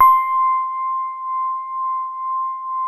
E-PIANO 1
TINE SOFT C5.wav